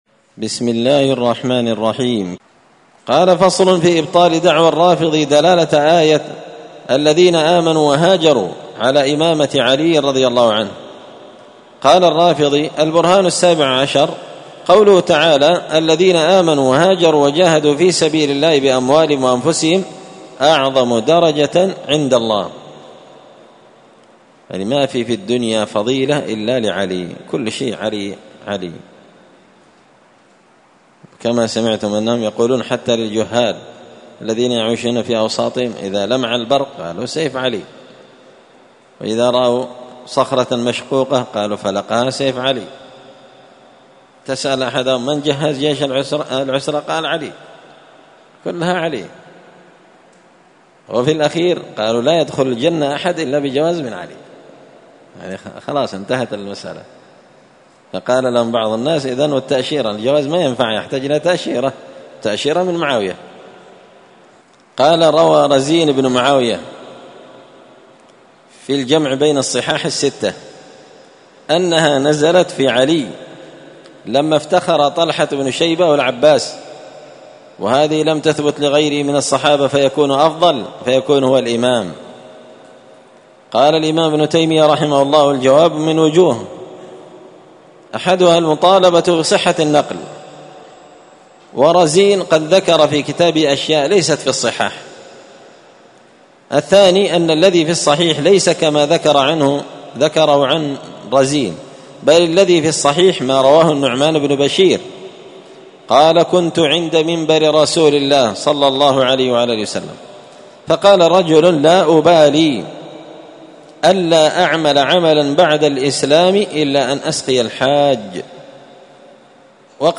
الأحد 11 صفر 1445 هــــ | الدروس، دروس الردود، مختصر منهاج السنة النبوية لشيخ الإسلام ابن تيمية | شارك بتعليقك | 65 المشاهدات